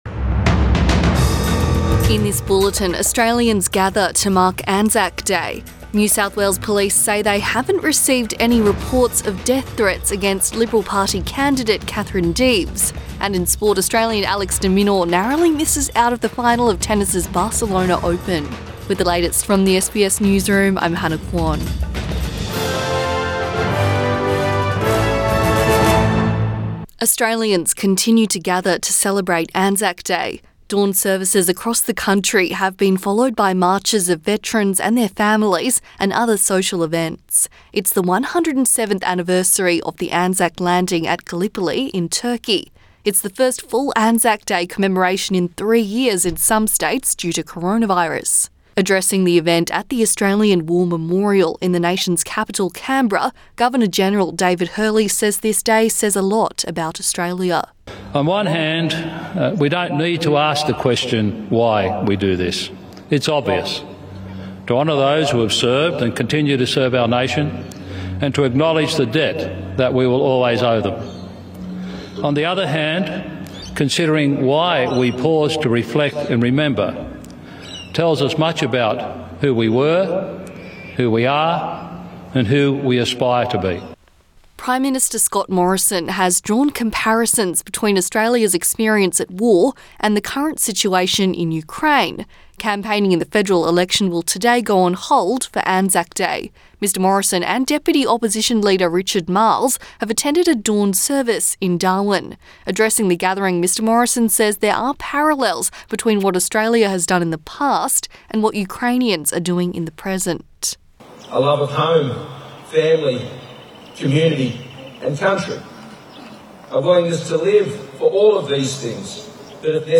Midday bulletin 25 April 2022